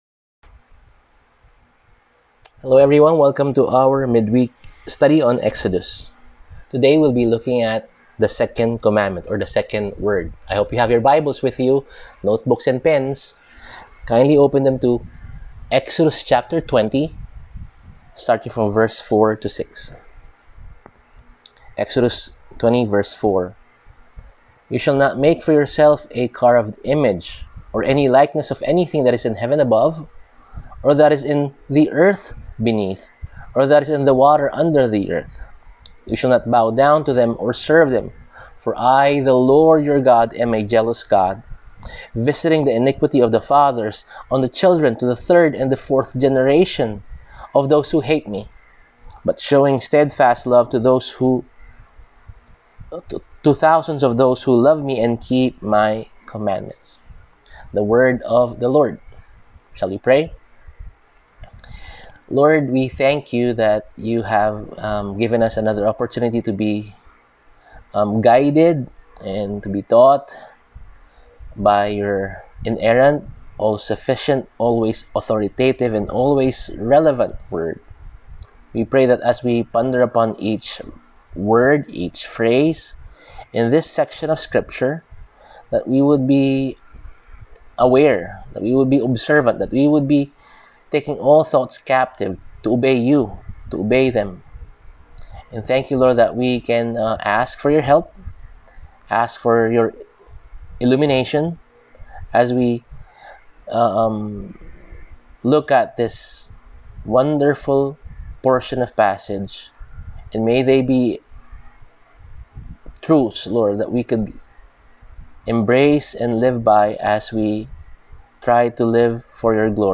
Service: Midweek Sermon